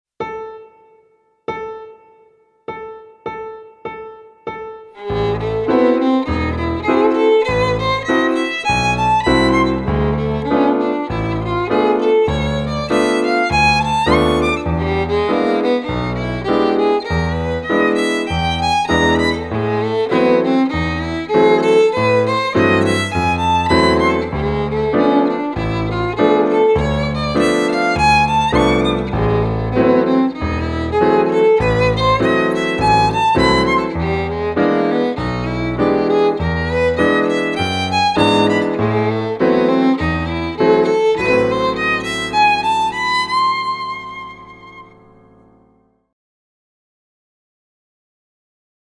-Arpegios y acordes: